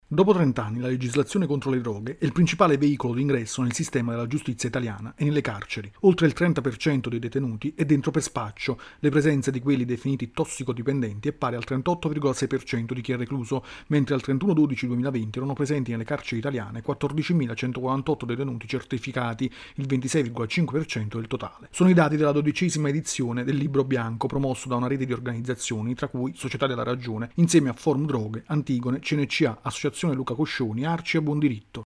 Presentato il Libro Bianco sulle droghe promosso da un cartello di associazioni. Il servizio